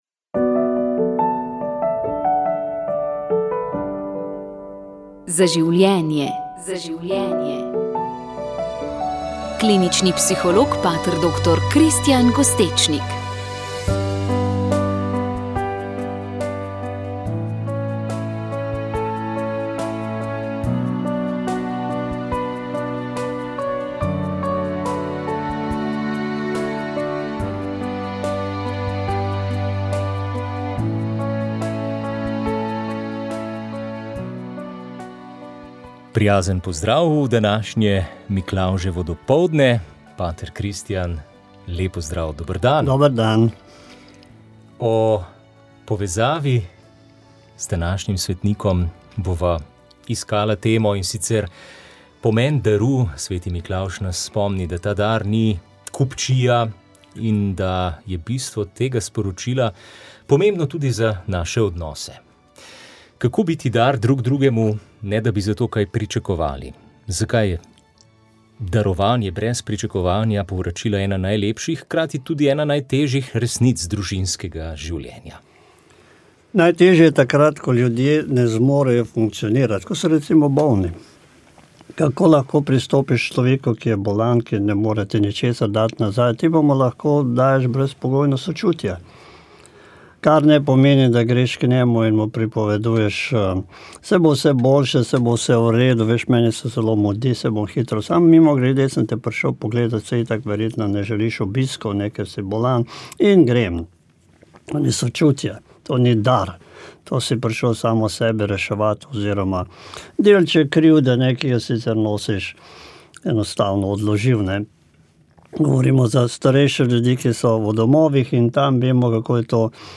Praznovanje je potekalo pri Svetem Jožefu v Celju.
nagovor